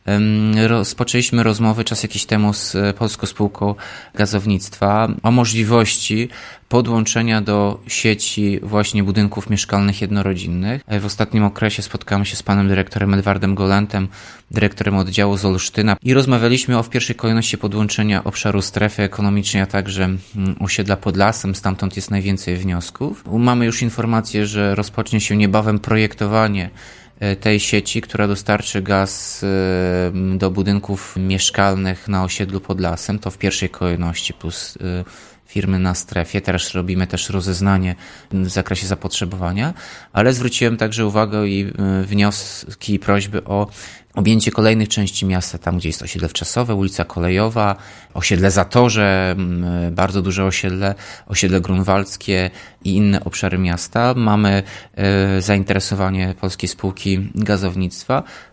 – Inwestujemy w ekologiczne paliwo, by zmniejszyć ilość zanieczyszczeń w powietrzu – mówi Tomasz Andrukiewicz, prezydent miasta.